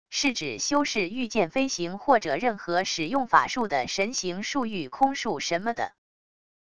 是指修士御剑飞行或者任何使用法术的神行术御空术什么的wav音频